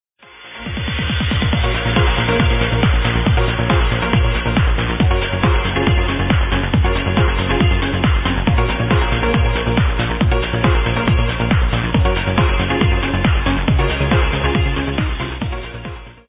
This one's more like a dub.